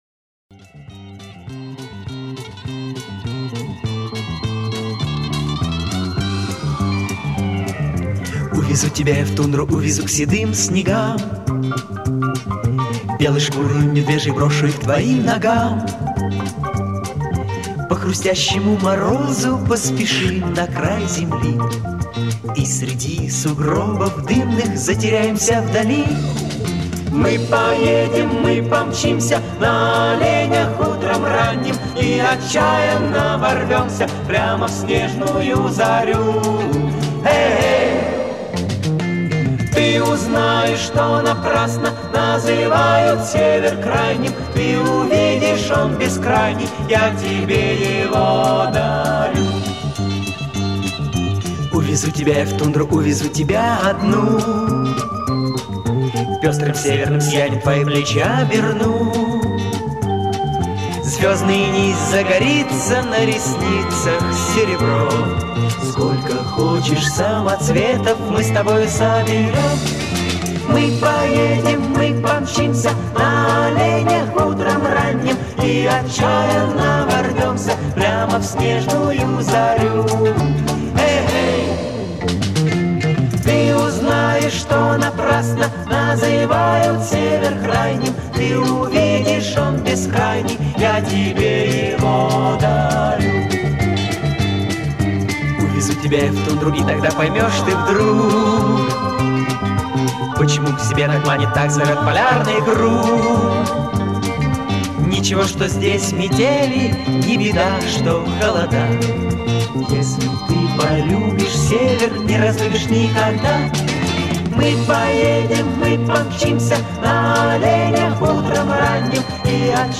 Вокал - точно я, это 100%, а вот инструментал, уже не помню.